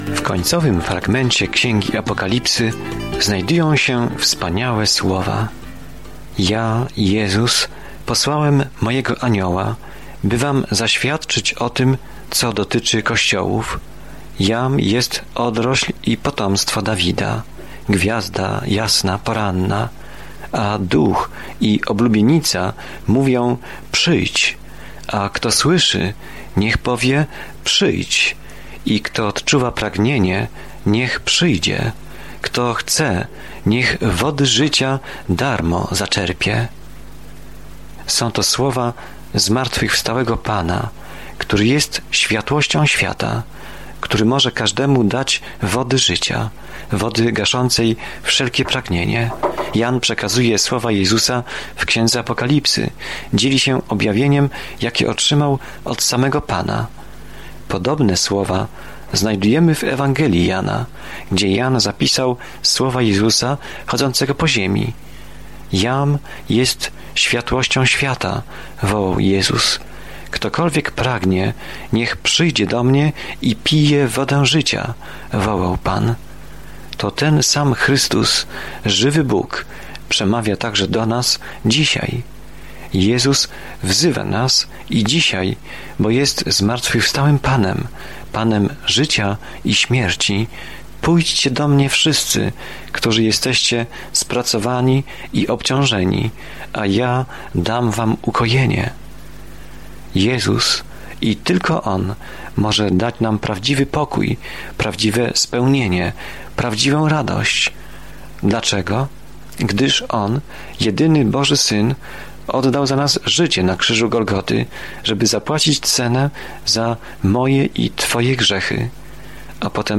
Scripture Revelation 22:18-21 Day 45 Start this Plan About this Plan Apokalipsa św. Jana opisuje koniec rozległego planu dziejów, przedstawiając obraz tego, jak zło zostanie ostatecznie uporane, a Pan Jezus Chrystus będzie rządził z całą władzą, mocą, pięknem i chwałą. Codziennie podróżuj przez Objawienie, słuchając studium audio i czytając wybrane wersety słowa Bożego.